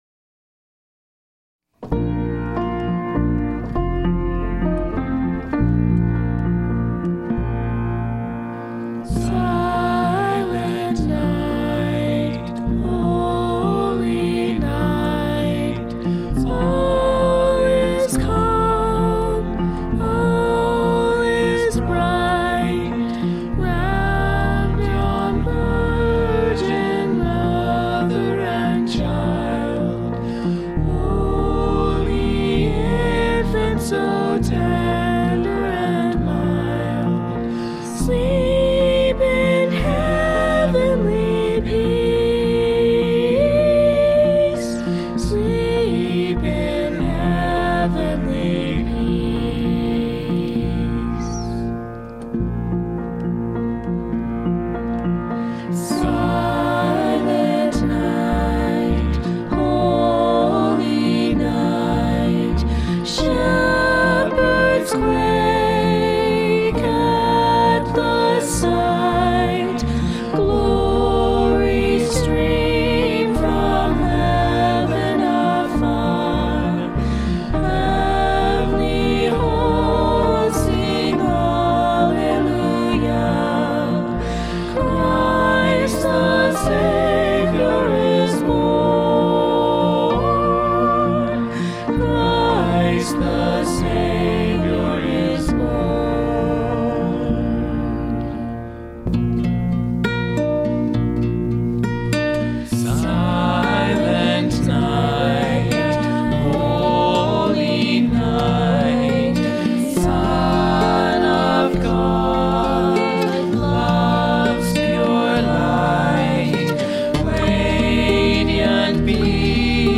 My kids did the instrumental tracks: piano, guitar, and bass. I did the vocals with one of my sons and a daughter.